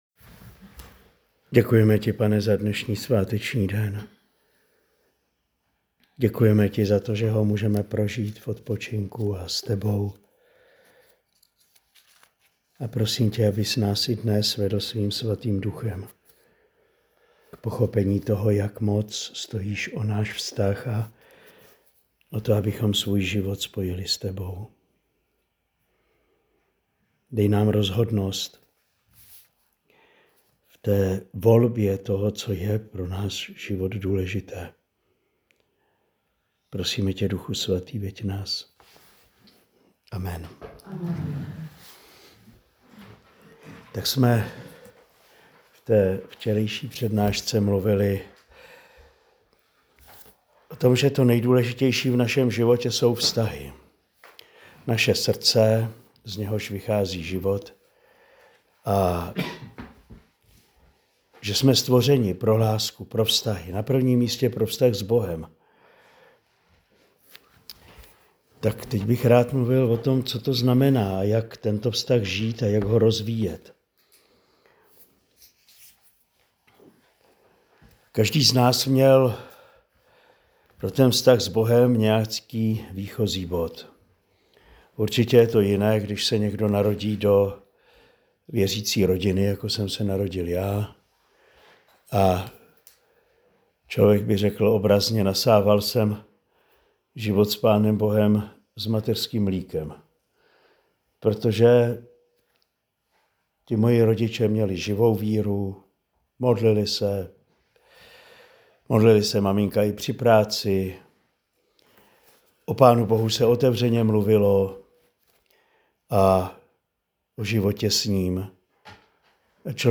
Pátá promluva z duchovní obnovy pro manžele v Kostelním Vydří v únoru 2025.